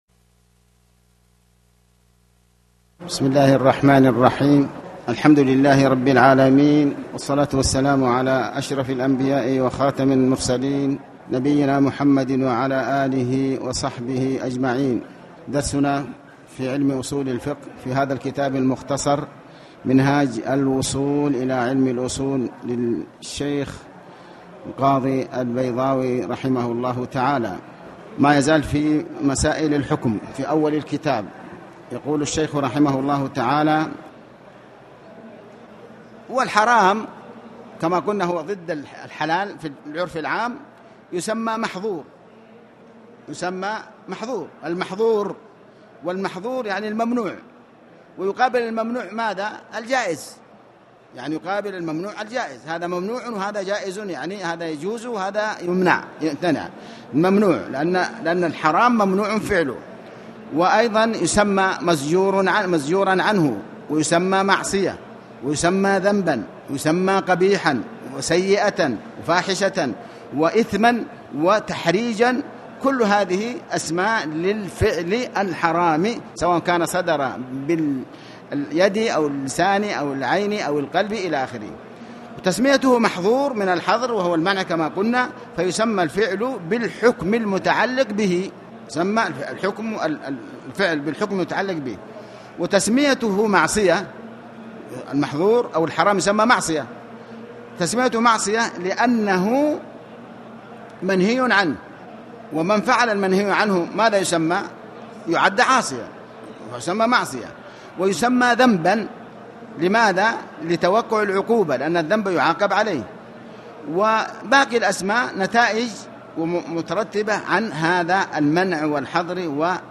تاريخ النشر ١٩ صفر ١٤٣٩ هـ المكان: المسجد الحرام الشيخ: علي بن عباس الحكمي علي بن عباس الحكمي الحكم وأقسامه